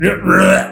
Play 『嘔吐聲』 - SoundBoardGuy
Play, download and share 『嘔吐聲』 original sound button!!!!
ou-tu-sheng.mp3